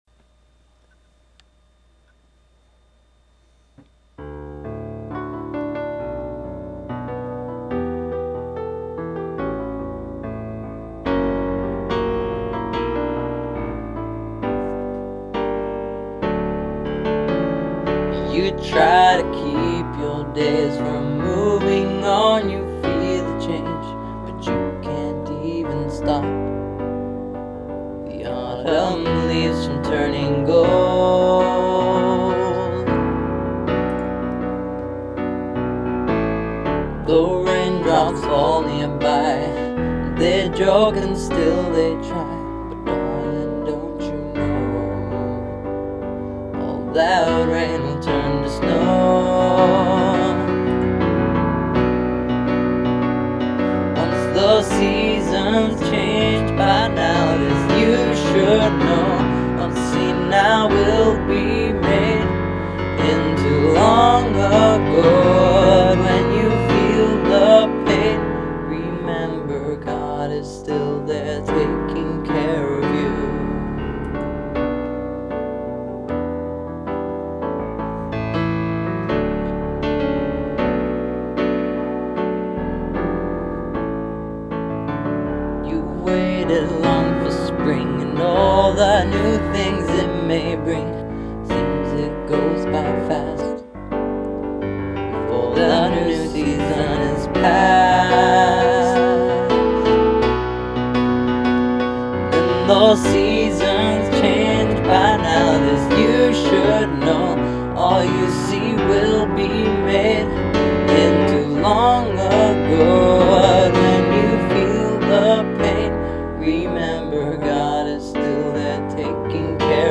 dance/electronic
Arrangements of spoken Proverbs
Experimental
Rock & Roll